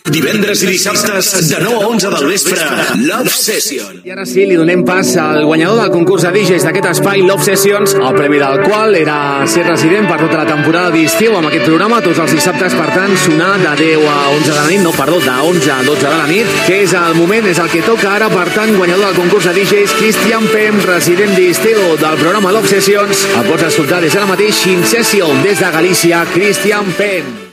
Indicatiu del programa
Gènere radiofònic Musical